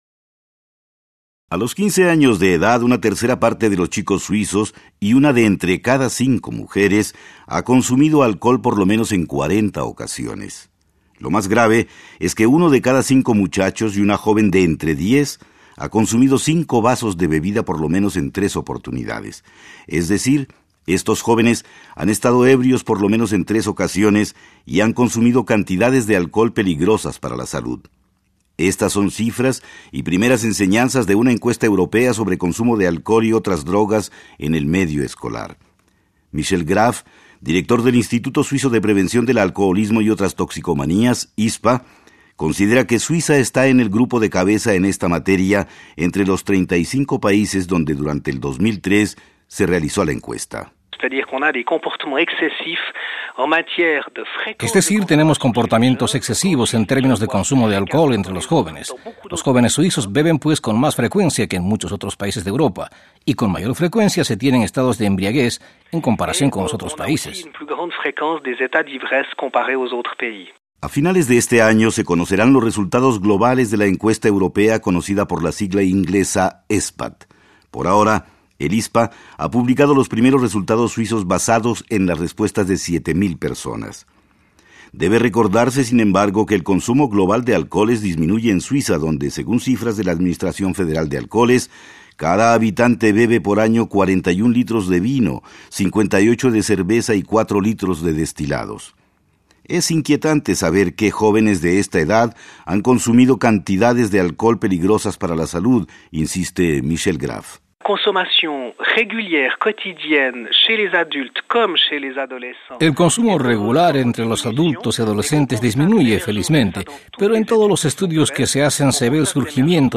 Las agencias suizas de prevención y salud se inquietan además por los hijos de padres alcohólicos y por la adicción entre la heterogénea población inmigrante. Un reportaje